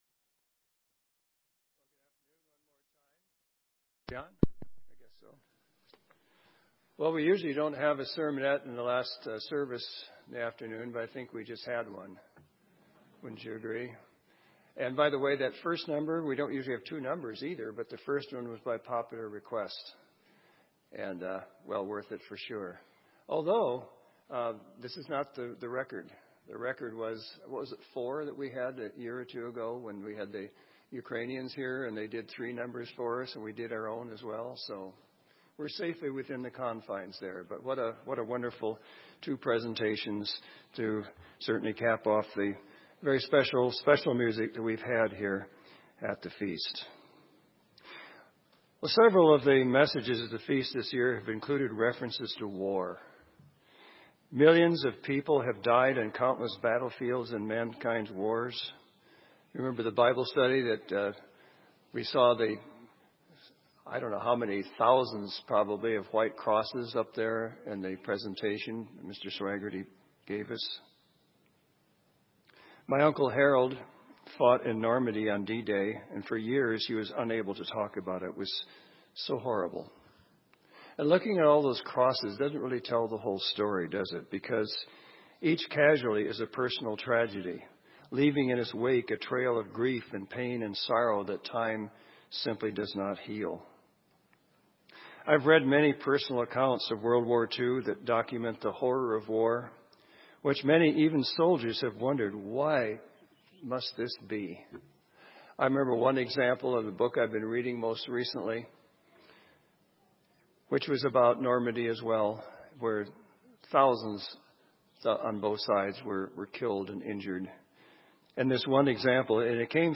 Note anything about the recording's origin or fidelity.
This sermon was given at the Bend, Oregon 2016 Feast site.